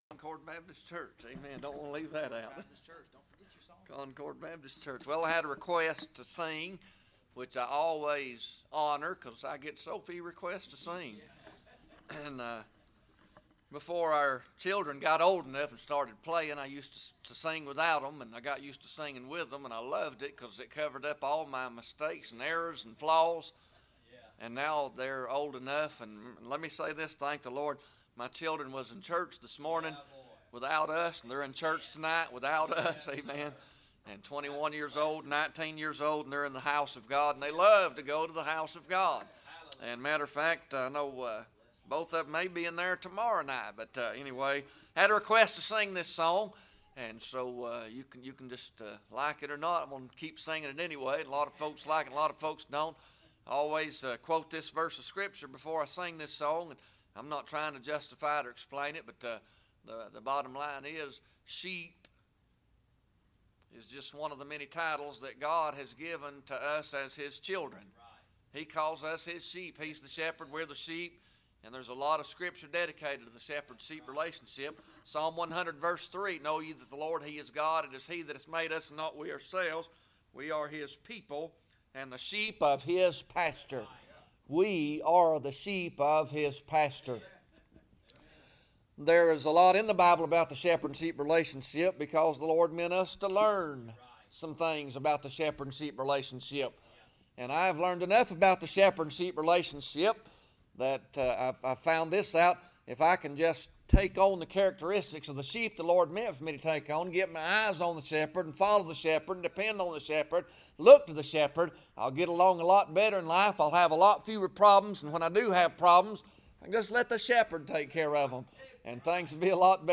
1 Peter 5:7 Service Type: Sunday Evening Bible Text